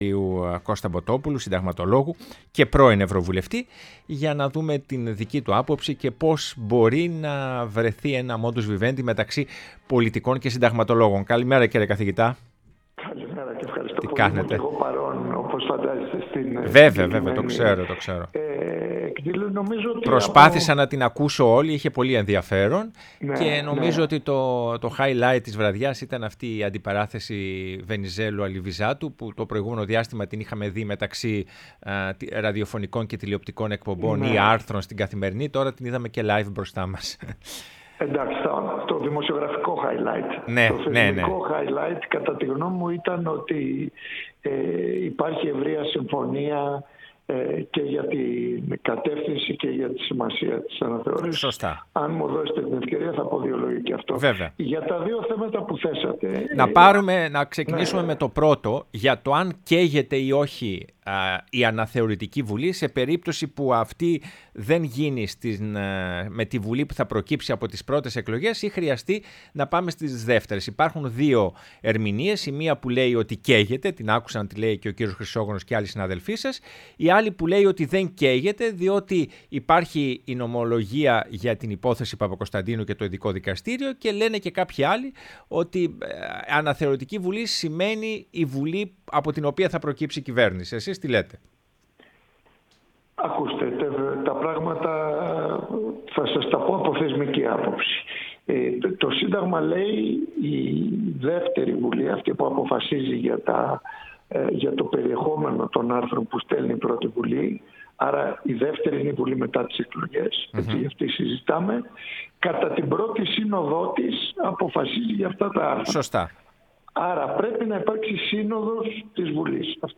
Κώστας Μποτόπουλος, Συνταγματολόγος, πρώην ευρωβουλευτής μίλησε στην εκπομπή «Ναι, μεν Αλλά»